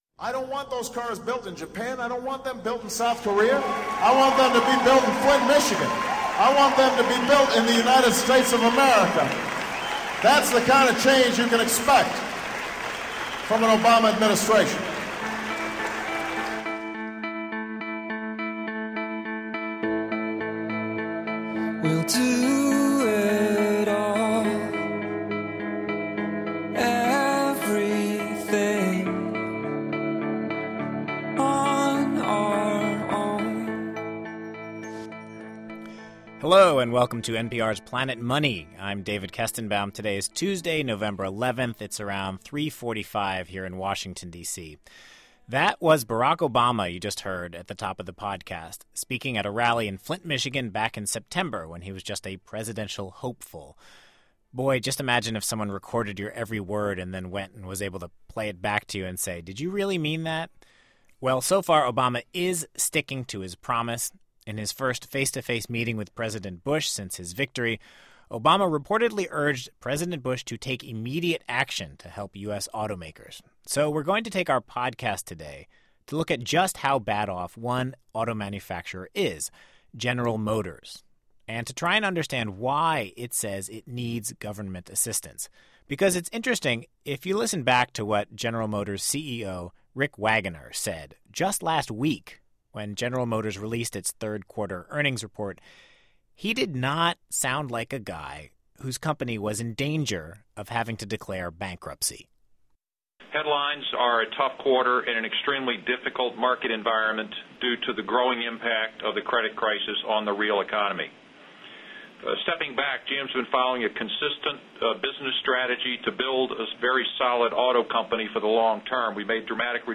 President-elect Barack Obama wants to give the auto industry more help. One economist says they need it to survive. And an auto worker from Detroit calls for an economist housecall.